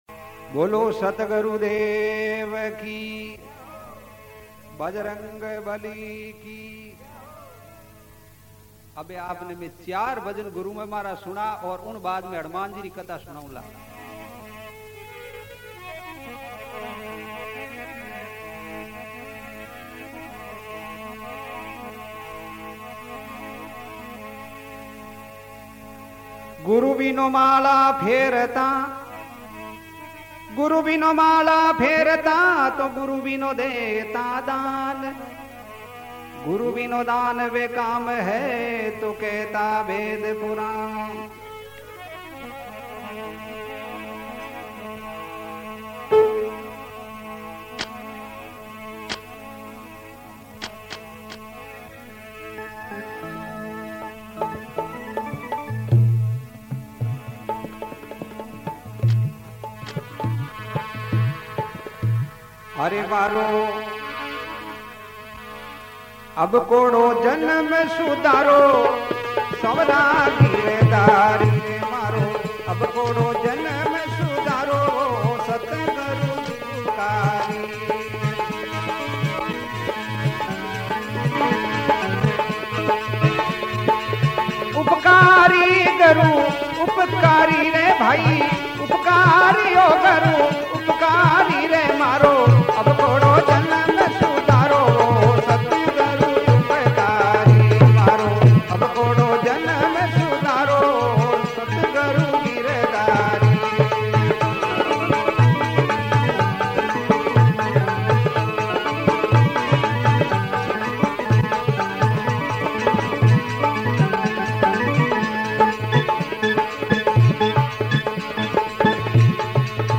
Rajasthani Songs
Satsang Bhajan